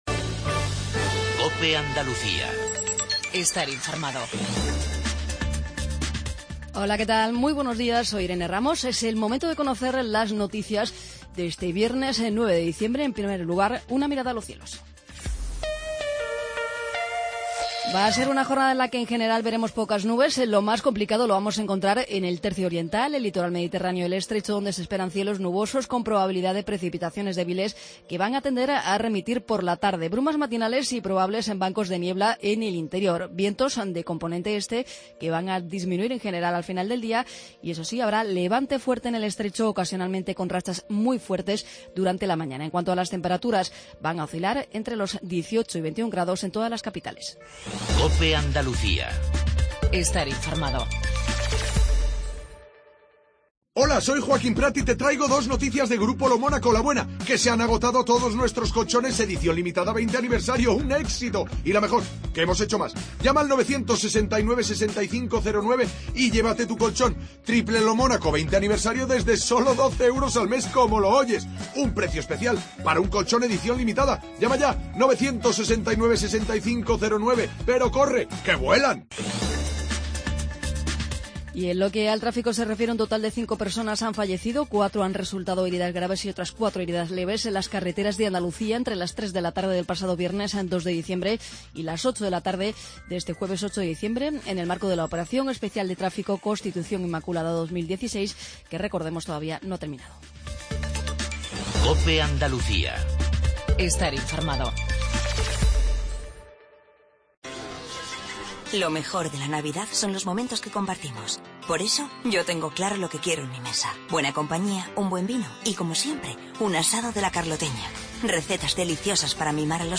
INFORMATIVO REGIONAL/LOCAL MATINAL 7:20